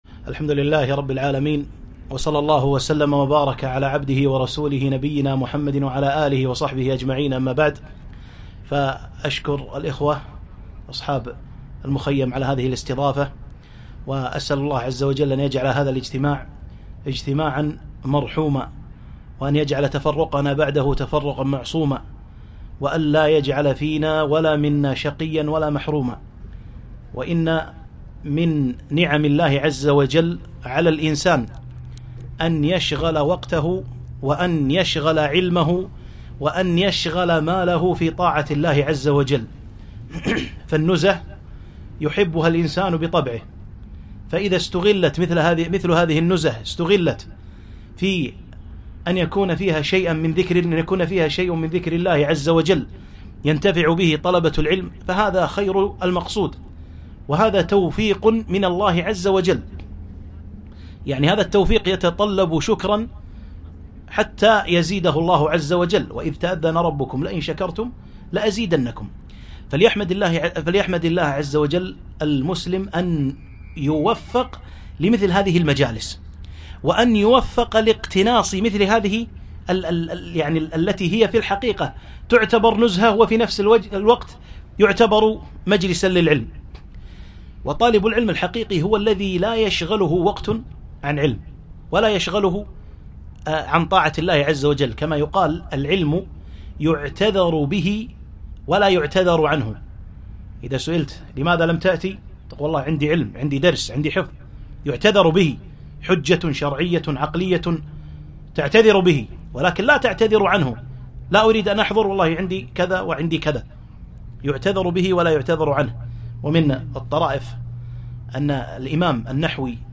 محاضرة - التمسّك بالسنة وأثره على المسلم